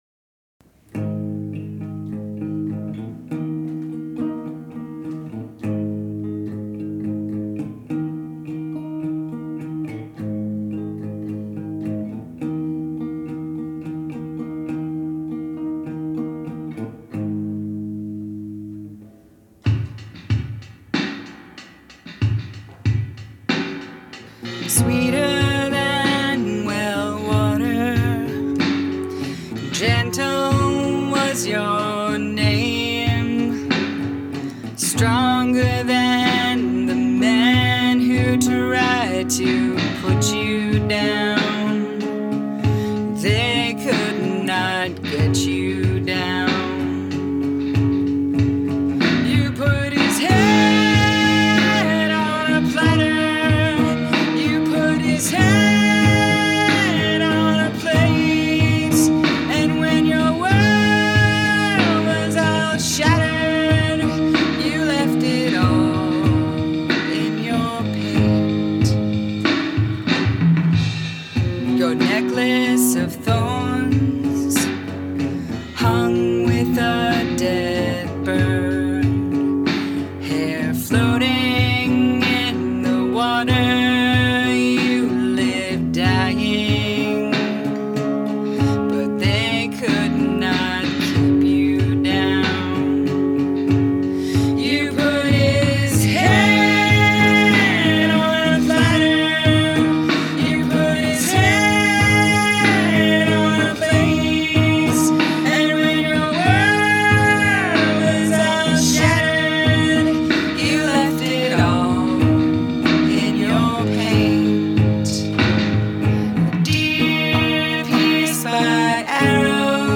guitar
Lo-fi doom rock isn't my bag at all.